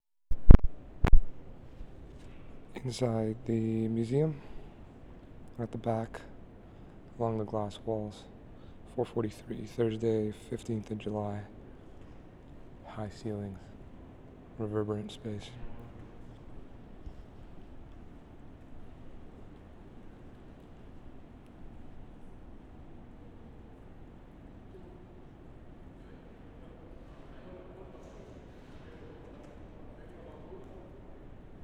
MUSEUM OF ANTHROPOLOGY, JULY 15/2010
Inside the MOA, 0:31
3. Track ID @ intro, high ceilings - reverberant space.